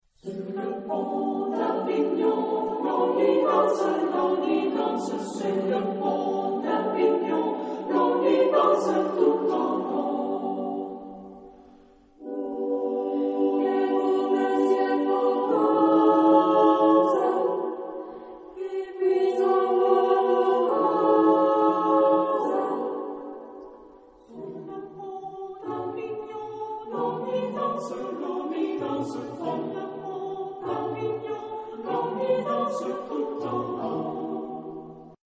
Type de choeur : SATB  (4 voix mixtes )
Solistes : Soprano (1)  (1 soliste(s))
Tonalité : sol majeur